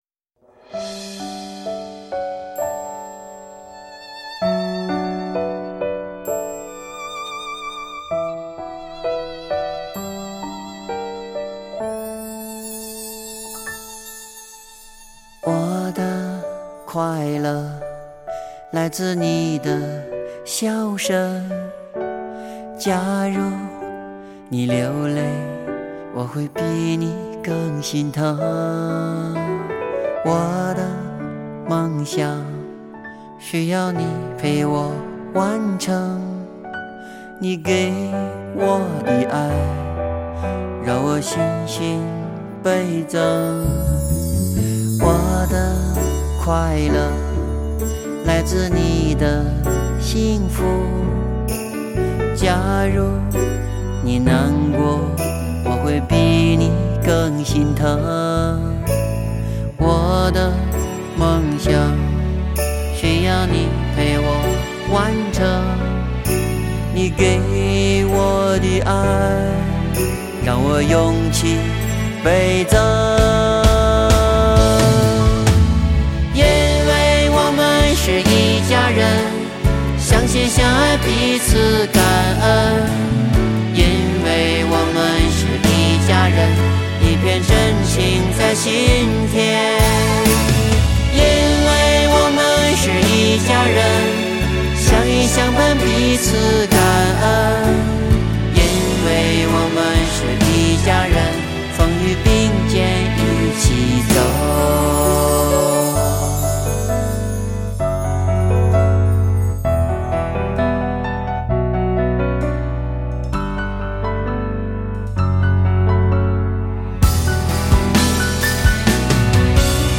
佛音 诵经 佛教音乐 返回列表 上一篇： 南无阿弥陀佛(精进